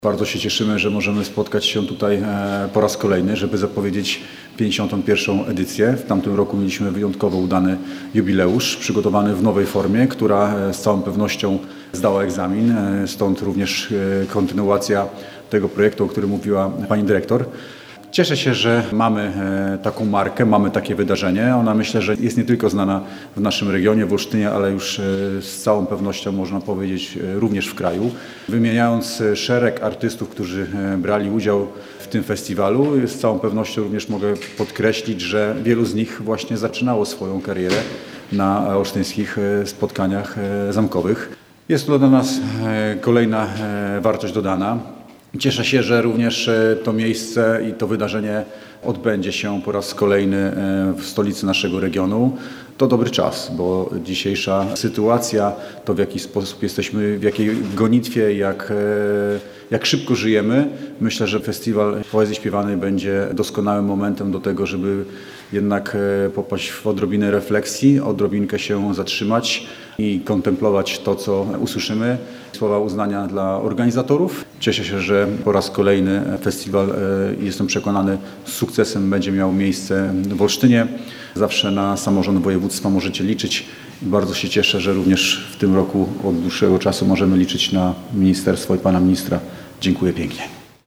– podkreślał marszałek województwa warmińsko-mazurskiego Marcin Kuchciński.